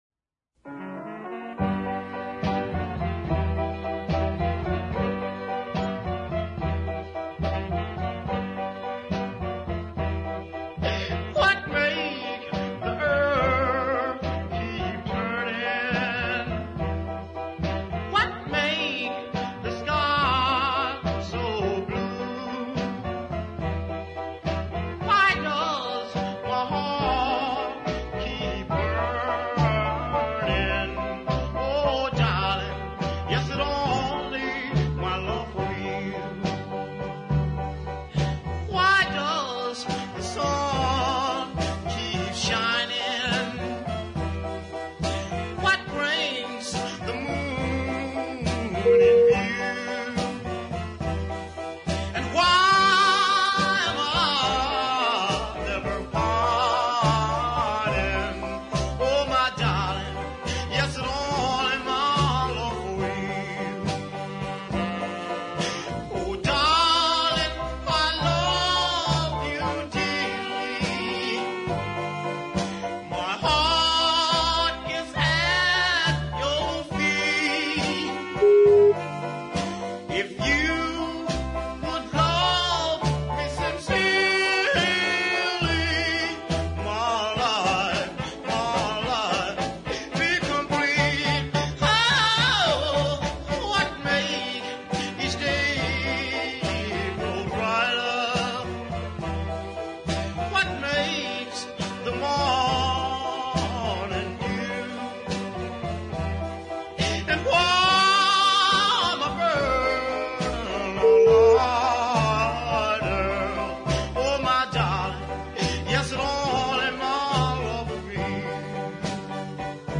soul cut R & B